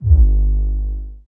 Bass Fail Sync.wav